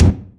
giant_ball02.mp3